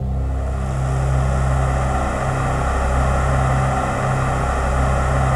XXL 800 Pads